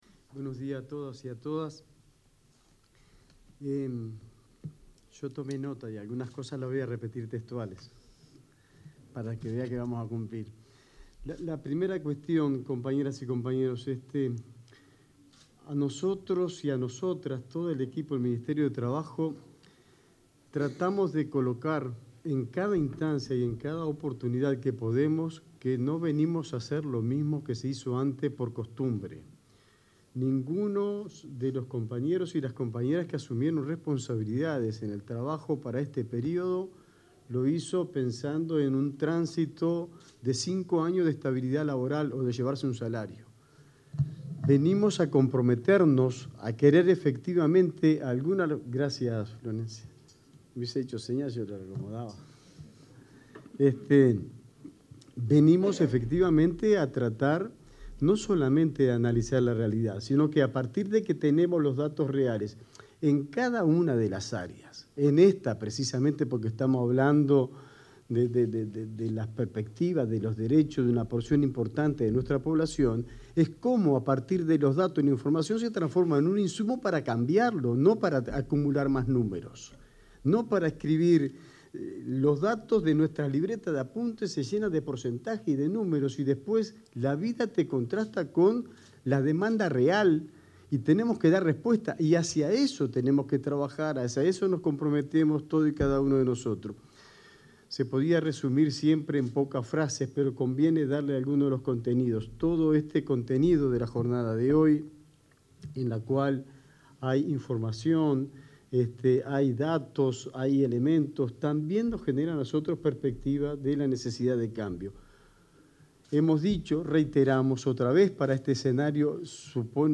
Palabras del ministro de Trabajo, Juan Castillo
El ministro de Trabajo, Juan Castillo, se expresó en el encuentro Afrodescendencia y Empleo, en el marco del Mes de la Afrodescendencia, el Segundo